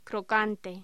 Locución: Crocante